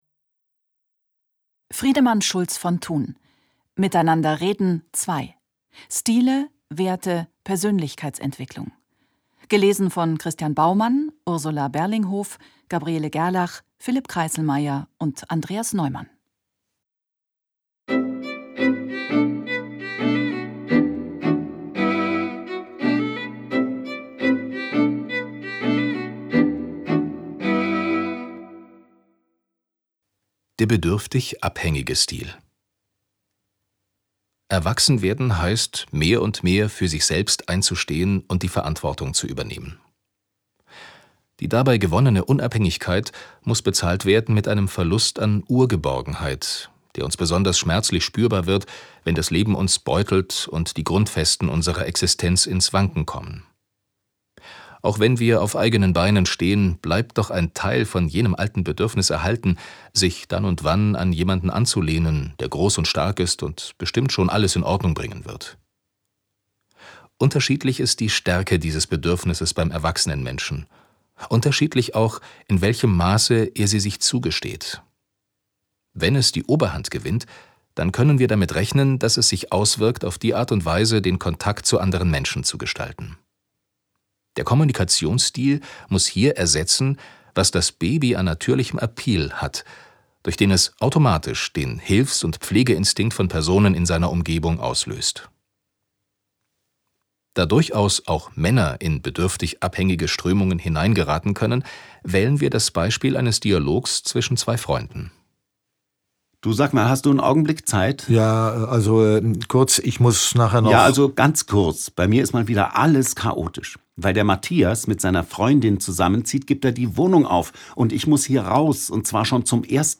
2015 | 3. Auflage, Gekürzte Ausgabe
Argon Hörbuch